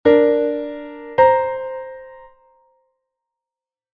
intervalos_harmonicos.mp3